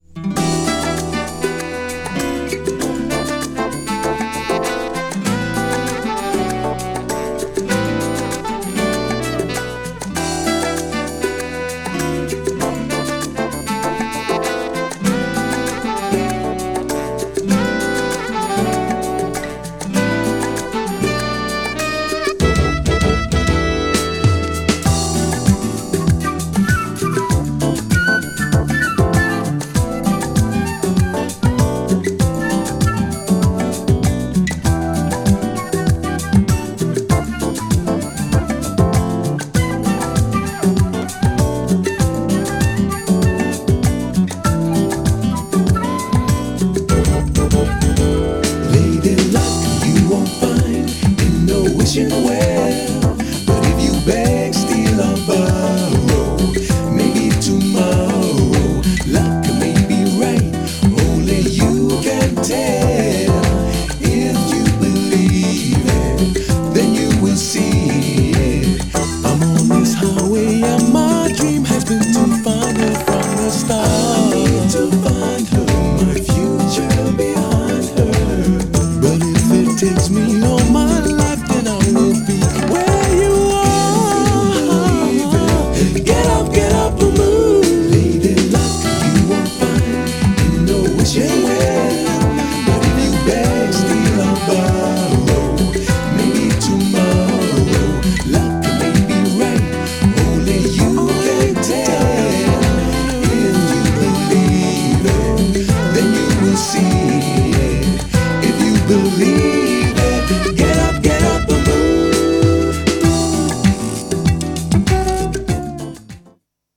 アーバンなダウンテンポのMIXどちらも極上!!
GENRE House
BPM 121〜125BPM